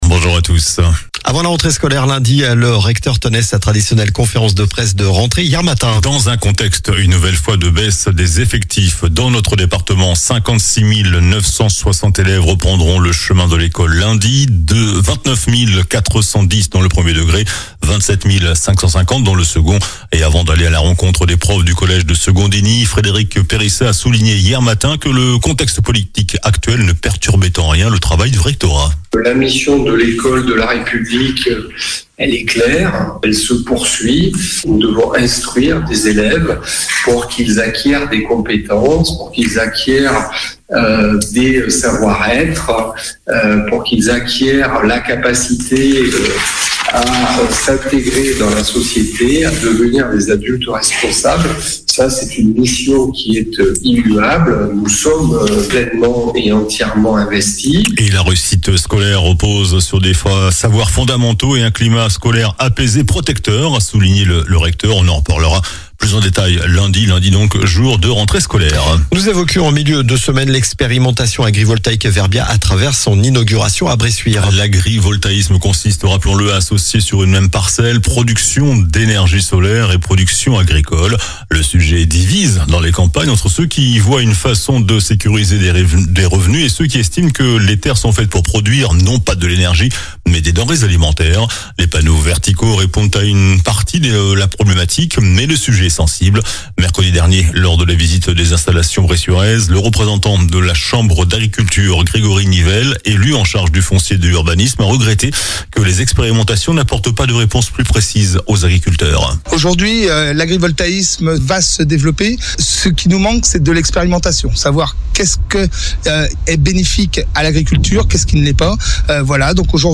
JOURNAL DU SAMEDI 30 AOÛT